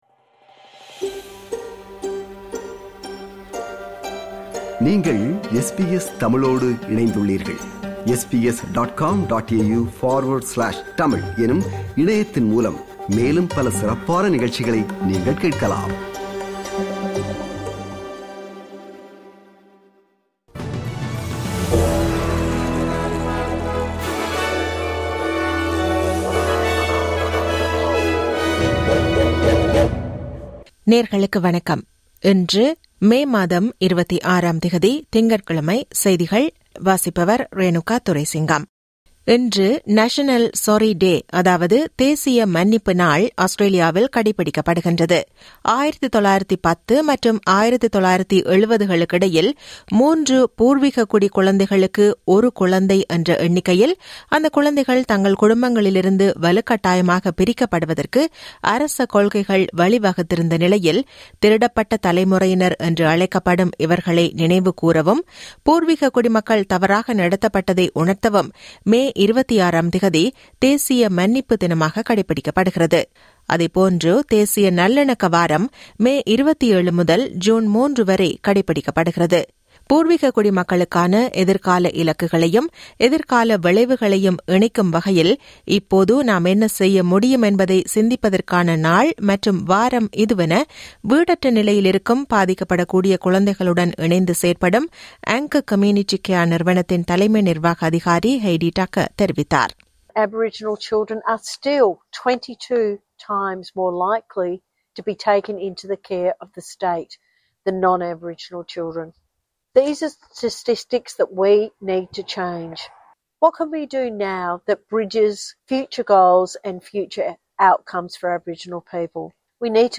SBS தமிழ் ஒலிபரப்பின் இன்றைய (திங்கட்கிழமை 26/05/2025) செய்திகள்.